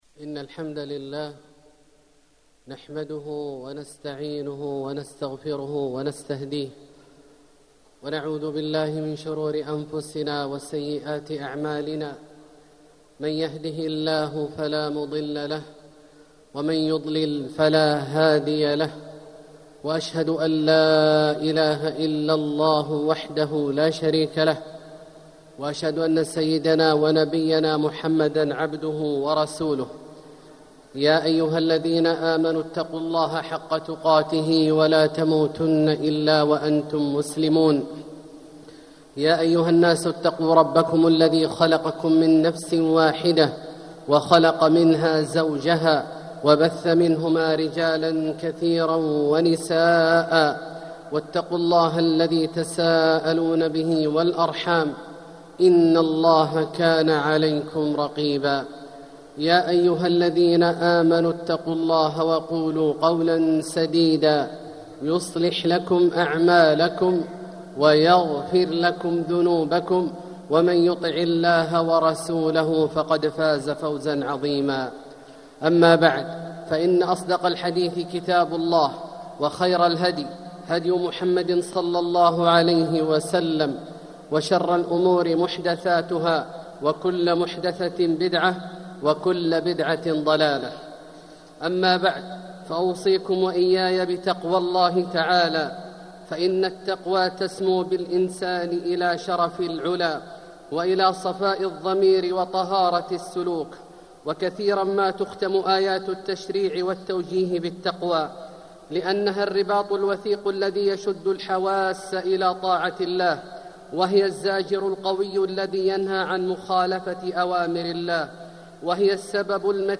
مكة: الموت والخاتمة الحسنة - عبد الله بن عواد الجهني (صوت - جودة عالية